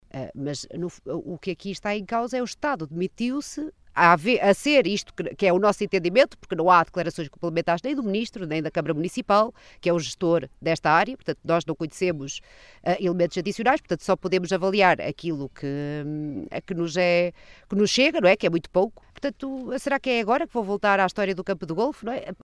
As preocupações foram explanadas no passado sábado, no âmbito de uma conferência de imprensa, na Praia da Ribeira, na albufeira do Azibo, no âmbito do Roteiro do Ambiente, que promoveu pelo distrito de Bragança.